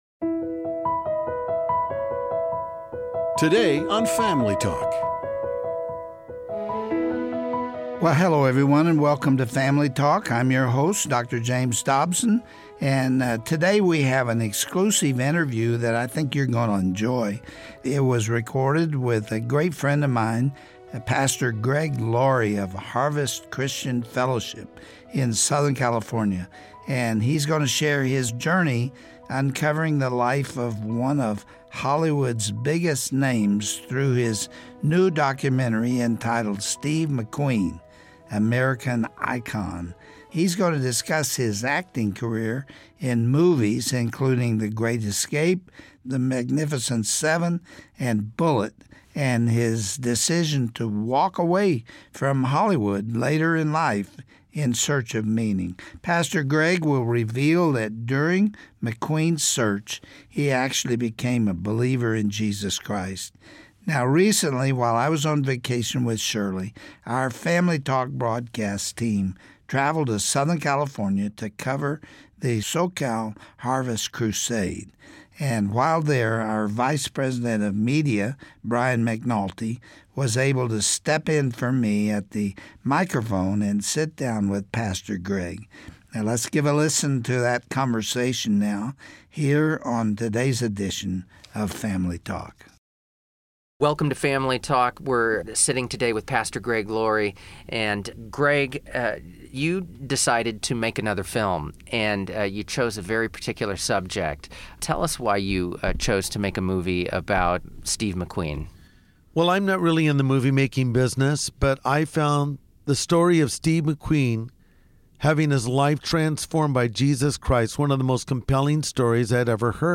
Today, Family Talk is thrilled to bring an exclusive interview with Pastor Greg Laurie of Harvest Christian Fellowship about his new movie entitled Steve McQueen: American Icon. Pastor Laurie will discuss McQueens distinguished acting career and his yearning to find true meaning in life that ended with a decision to follow Christ.